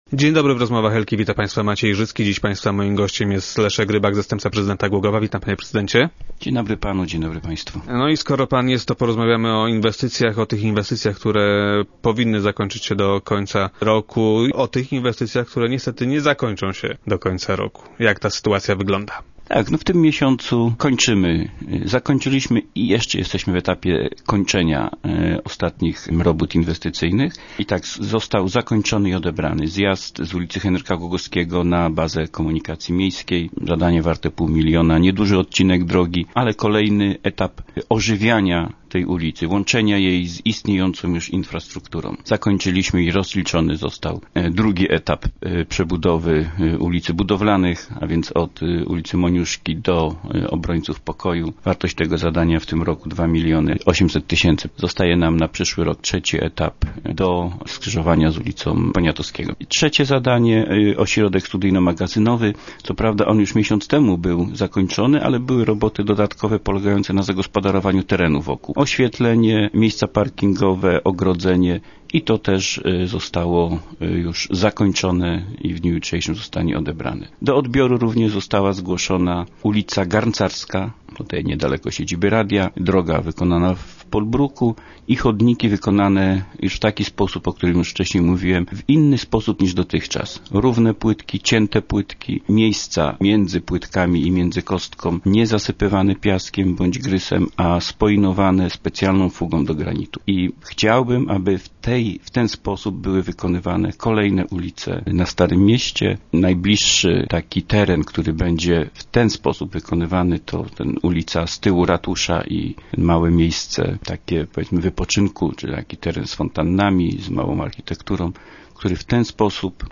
Myślę jednak, że do końca stycznia budynek zostanie odebrany od wykonawcy - zapewnia Leszek Rybak, zastępca prezydenta, który był gościem dzisiejszych Rozmów Elki.